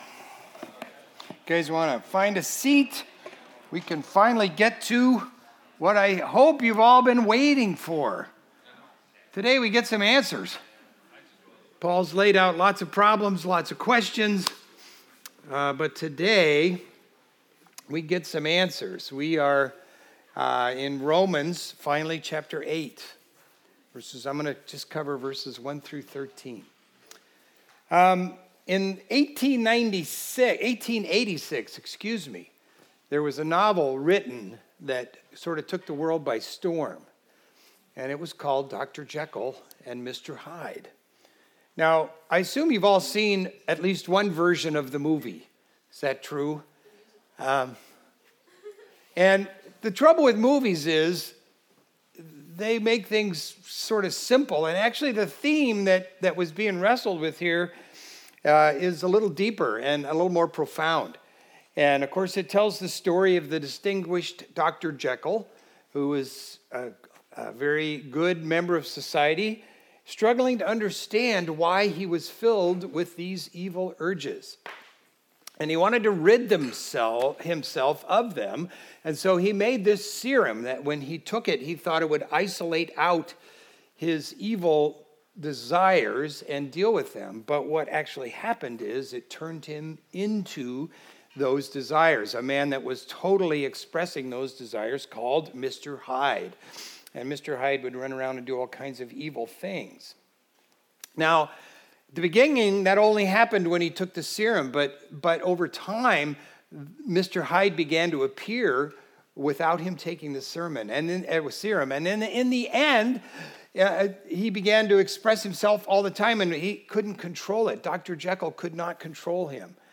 Video Audio Download Audio Home Resources Sermons Live Like You’re Dead Sep 07 Live Like You’re Dead The only way to live a life in harmony with God is realizing you are as helpless as a dead person.